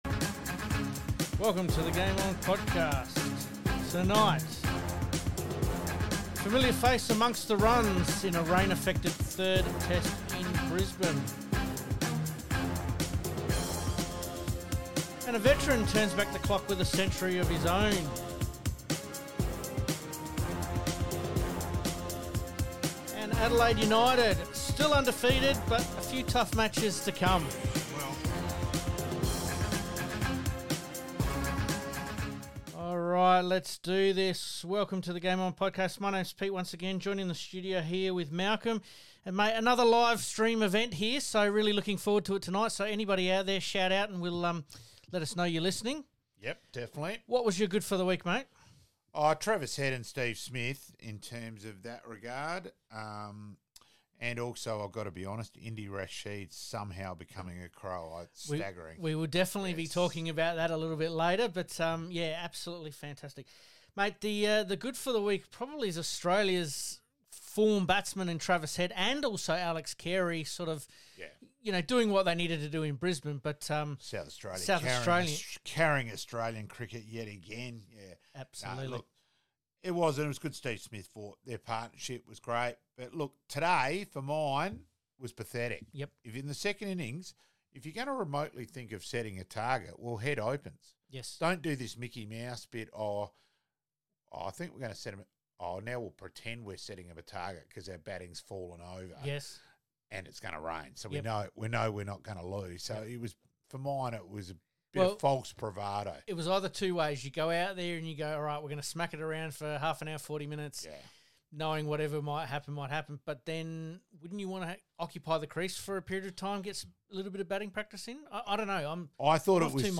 🔥 THIS WEEKS PODCAST is Available NOW & WAS LIVE🔥